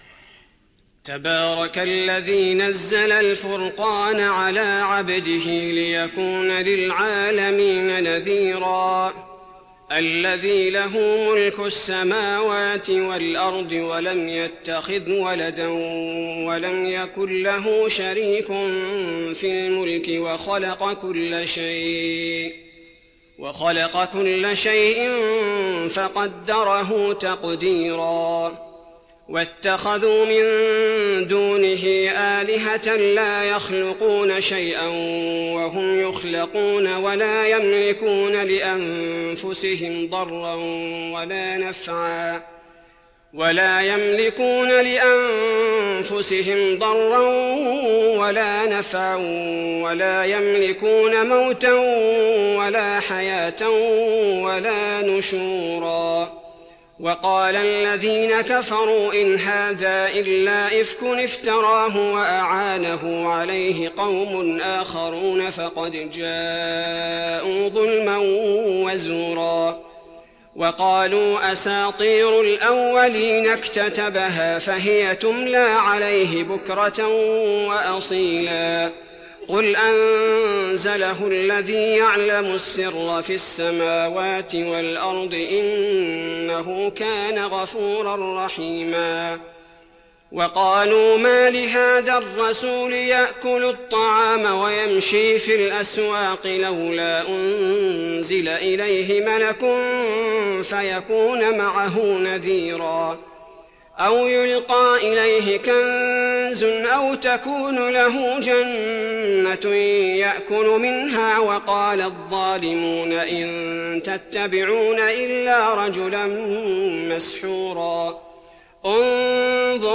تراويح رمضان 1415هـ من سورتي الفرقان كاملة و الشعراء (1-89) Taraweeh Ramadan 1415H from Surah Al-Furqaan and Ash-Shu'araa > تراويح الحرم النبوي عام 1415 🕌 > التراويح - تلاوات الحرمين